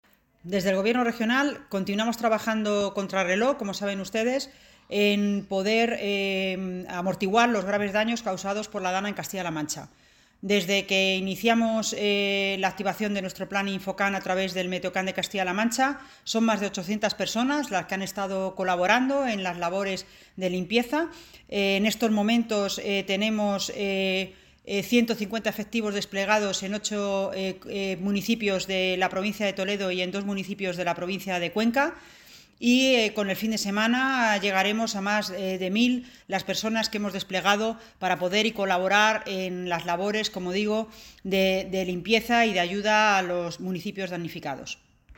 Consejería de Desarrollo Sostenible Viernes, 8 Septiembre 2023 - 1:30pm La consejera de Desarrollo Sostenible, Mercedes Gómez, ha destacado que son ya unos 800 profesionales del Plan INFOCAM los que hemos activado a día de hoy para colaborar en la adecuación de los servicios públicos principales y las vías que dan acceso a los mismos en los pueblos más afectados. Hoy están trabajando en ocho municipios de Toledo y dos de Cuenca. gomez_valoracion_infocam_viernes.mp3 Descargar: Descargar